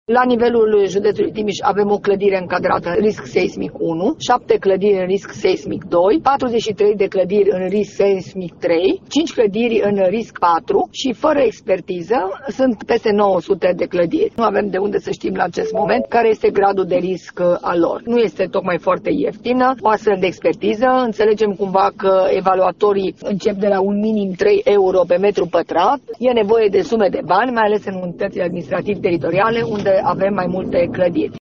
Inspectorul școlar general, Aura Danielescu, spune că primăriile trebuie să aloce bani de la buget pentru evaluarea acestor imobile, .